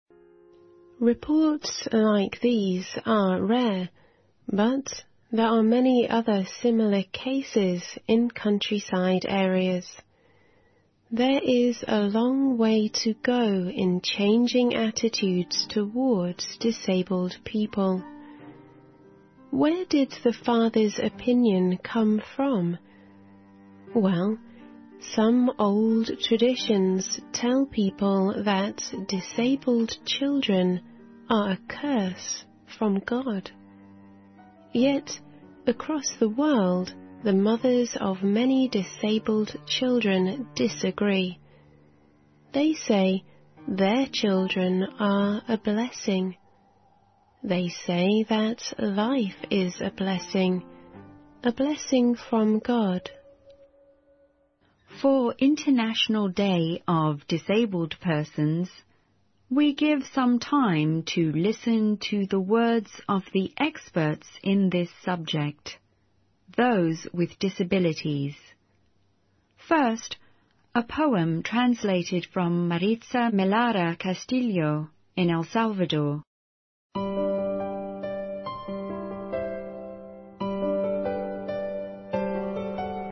环球慢速英语 第564期:国际残疾人日(6)